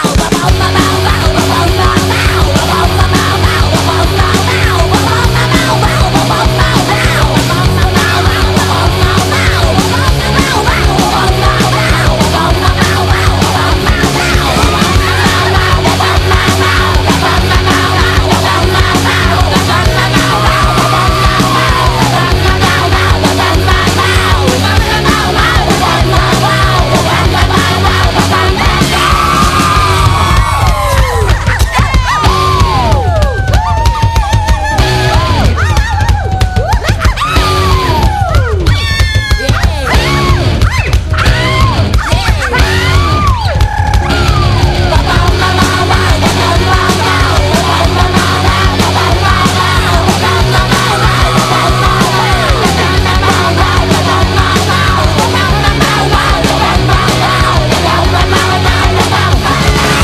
ROCK / 90''S～ / INDIE POP / POST ROCK (US)
ポスト・ロック・ファンクなインスト・バンド！